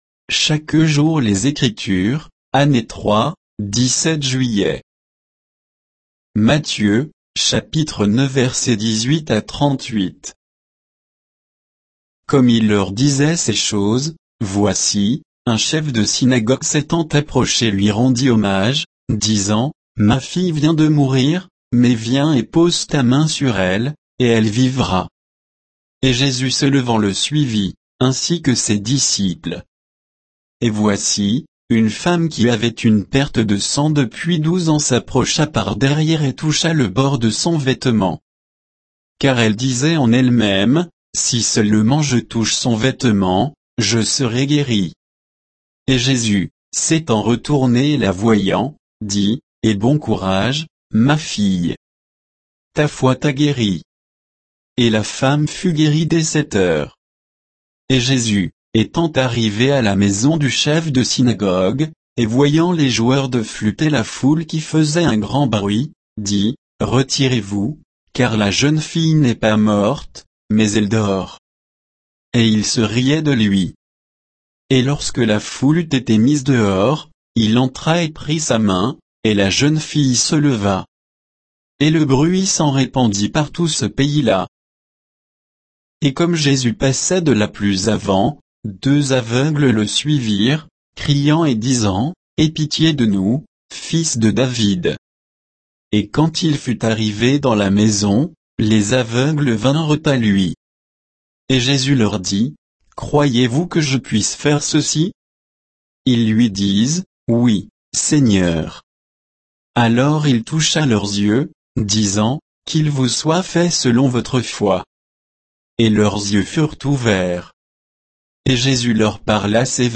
Méditation quoditienne de Chaque jour les Écritures sur Matthieu 9